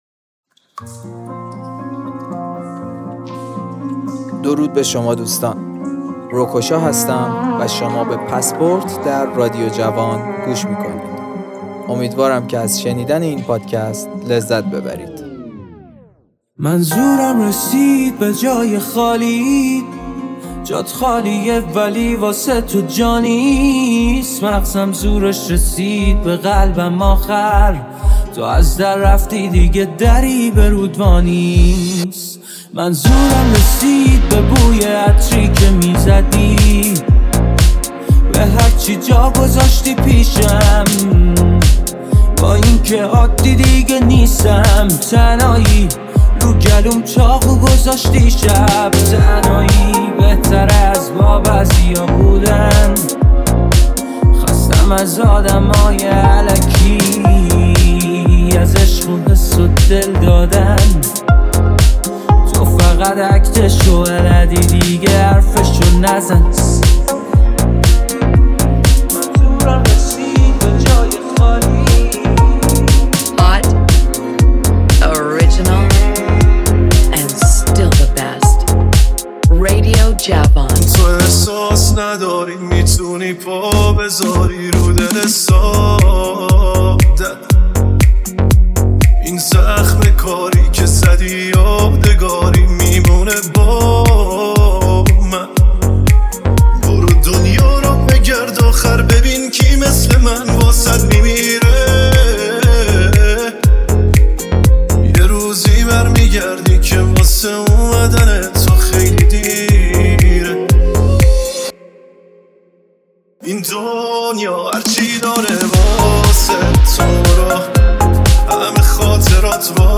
بیس دار
در سبک الکترونیک